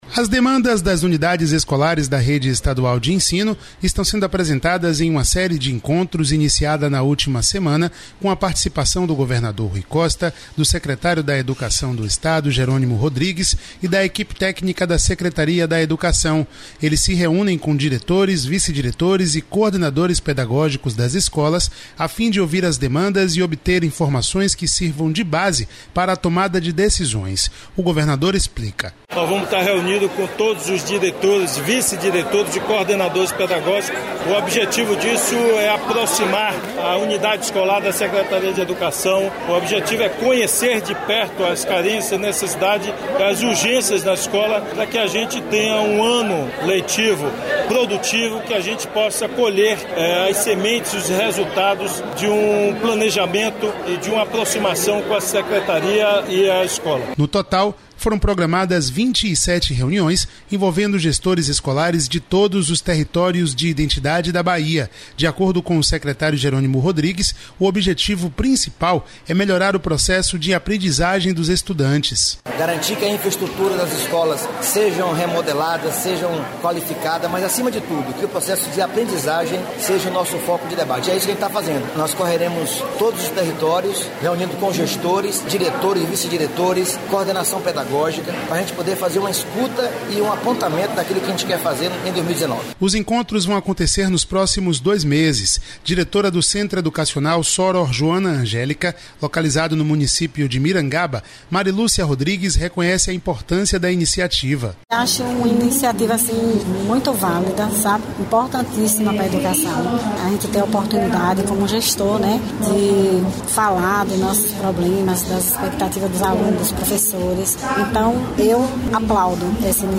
O governador explica (ouça o áudio abaixo).